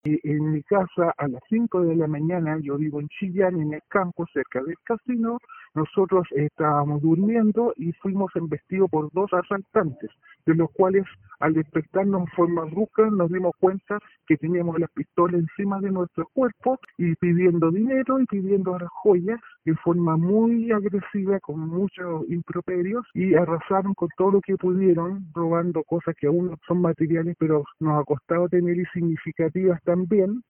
La mujer, quien pidió distorsionar su voz para resguardar su identidad por miedo a represalias, comentó que -junto a su familia- fueron despertados violentamente con arma en mano.